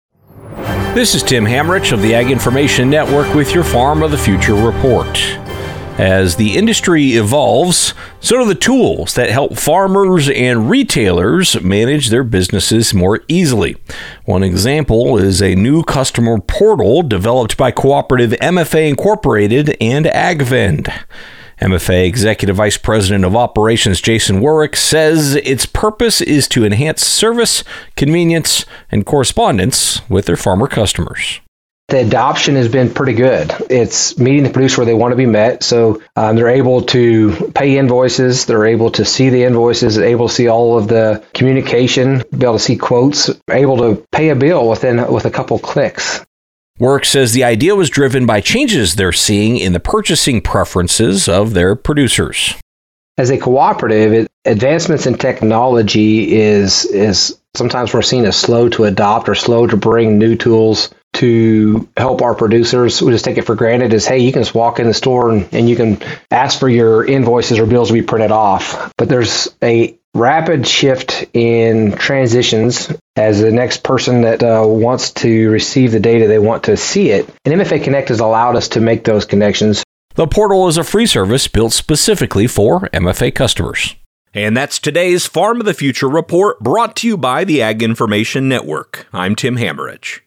News Reporter